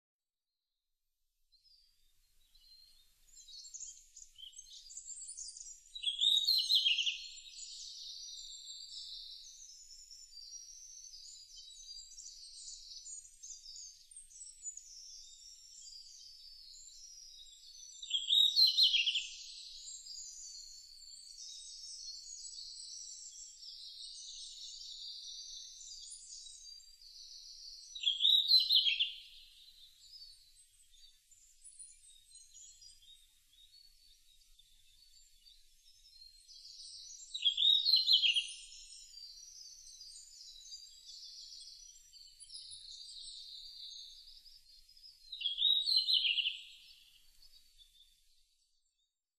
ルリビタキ　Tarsiger cyanurusツグミ科
片品村菅沼　alt=1750m  HiFi --------------
Windows Media Audio FILE MPEG Audio Layer3 FILE  Rec.: EDIROL R-09
Mic.: built-in Mic.
他の自然音：　 キクイタダキ・メボソムシクイ・ミソサザイ